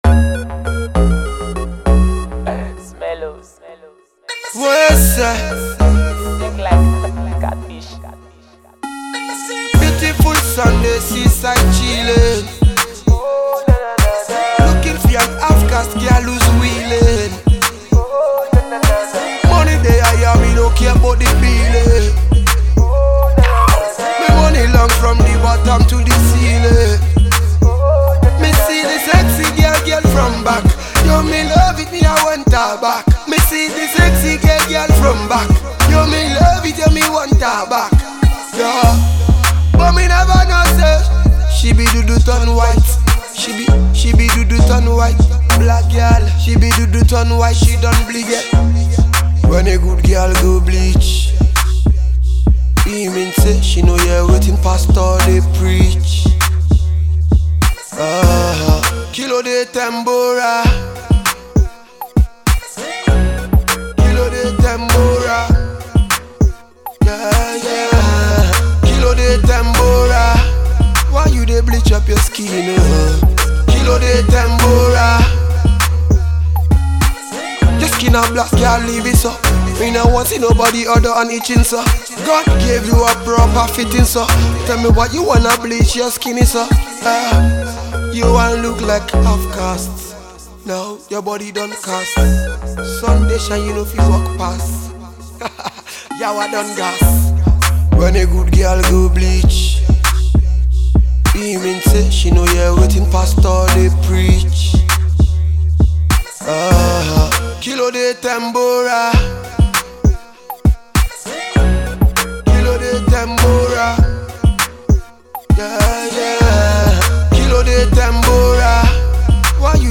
dope freestyle